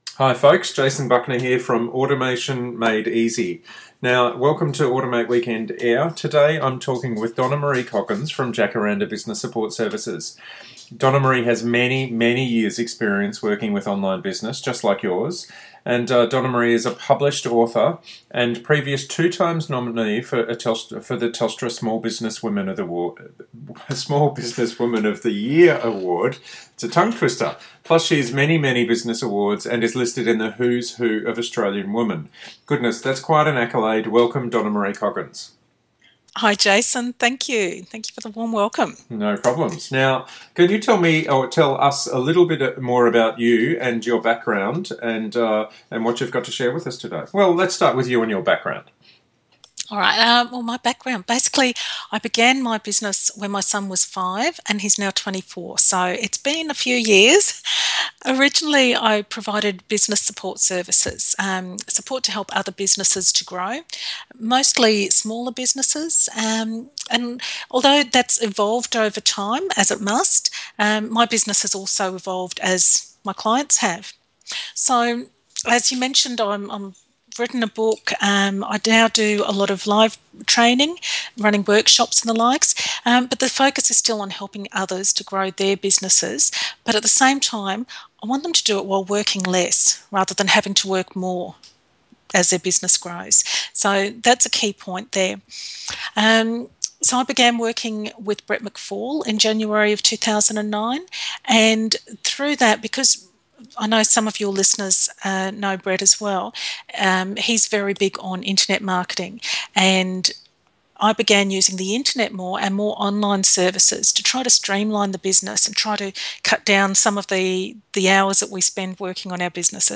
dm-interview.mp3